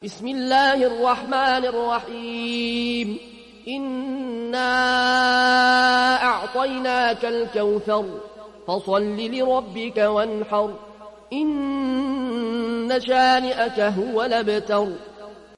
Riwayat Warsh from Nafi